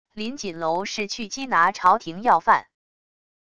林锦楼是去缉拿朝廷要犯wav音频生成系统WAV Audio Player